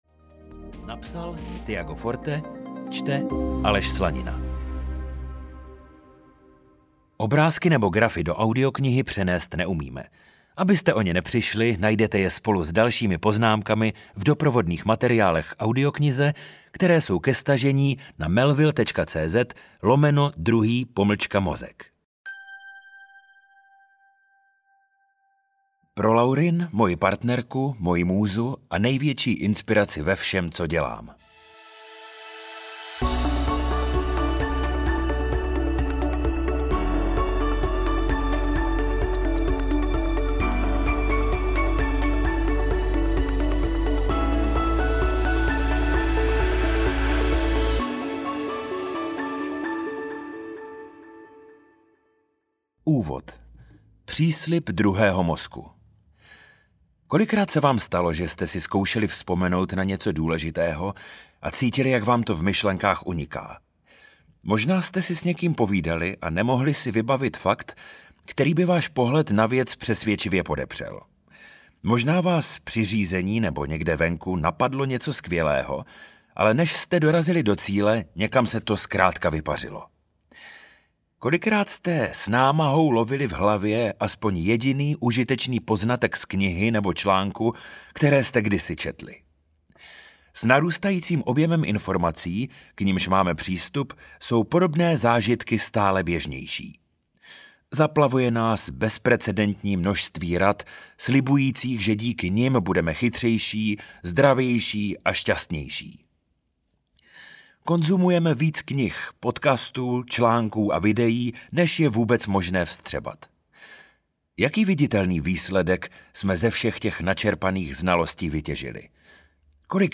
Pořiďte si druhý mozek audiokniha
Ukázka z knihy